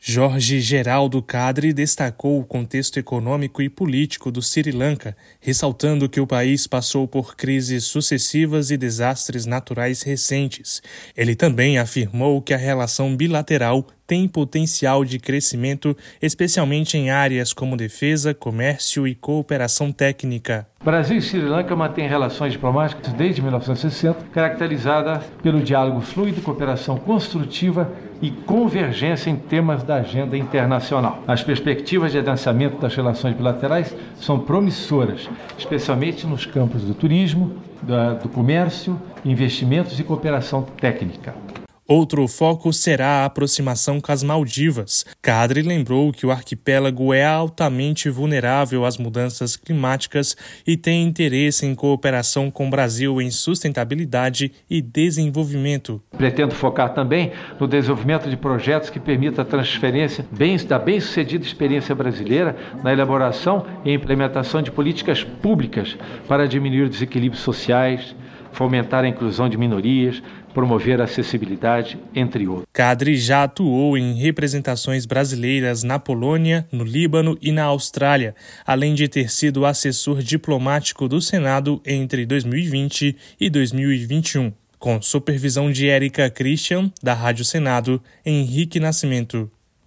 Na sabatina, o diplomata defendeu ampliar a cooperação técnica, fortalecer a atuação em defesa, diversificar o comércio e desenvolver projetos sociais, além de apoiar ações de combate ao crime organizado.